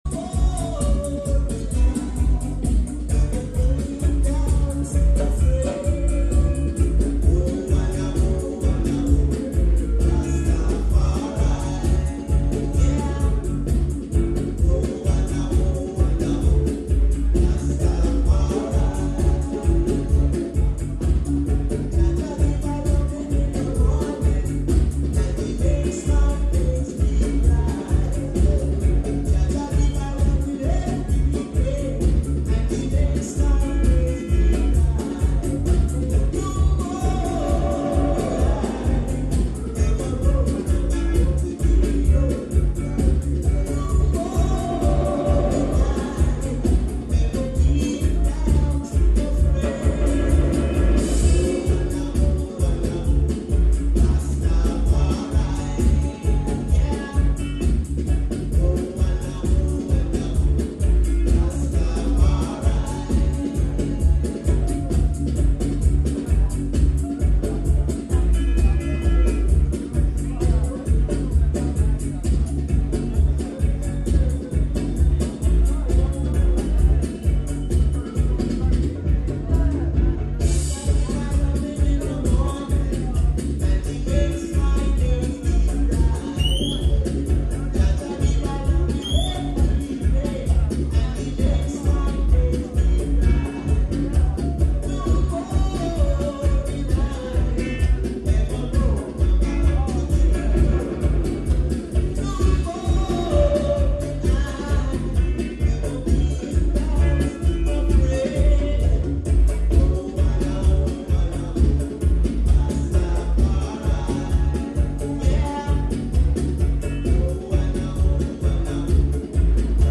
A few technical issues with the recording apologies.